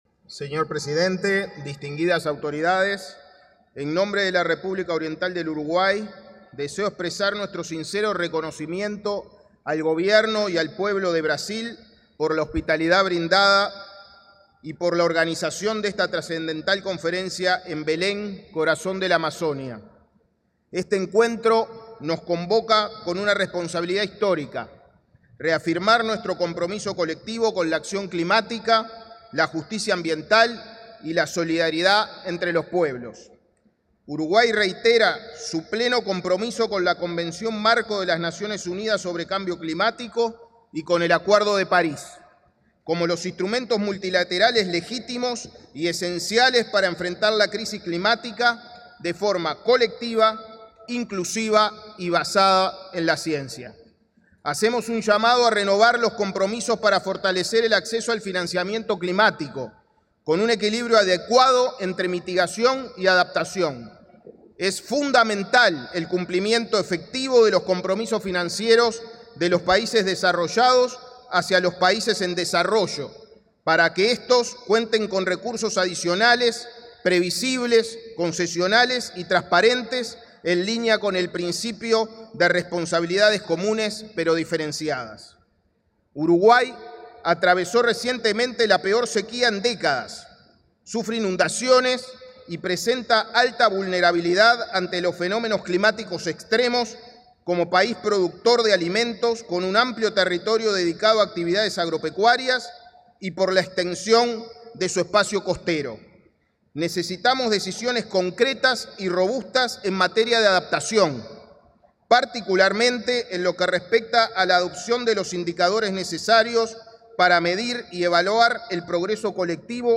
El ministro de Ambiente, Edgardo Ortuño, pronunció un discurso en la reunión plenaria de la cumbre COP30, en Belém, Brasil.